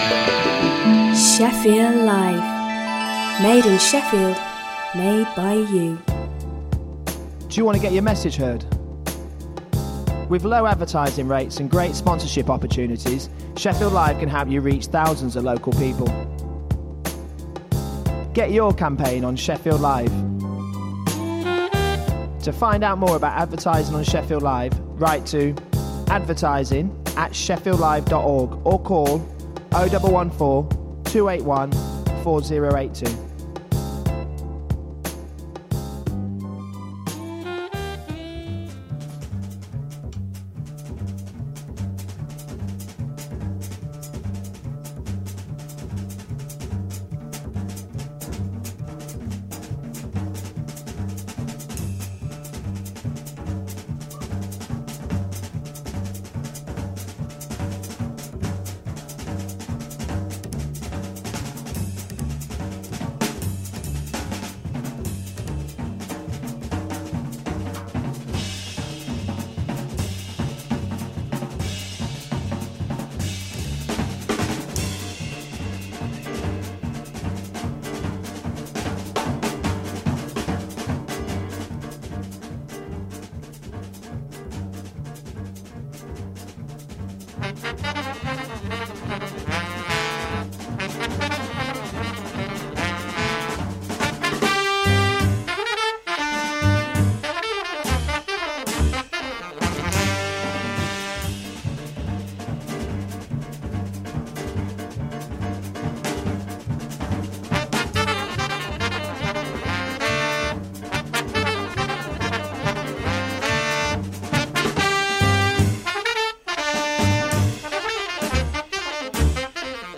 An extensive weekly exploration of modern jazz; be-bop, hard bop, West Coast, Latin, bossa nova, vocals, fusion and soul.